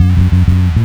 FINGERBSS5-R.wav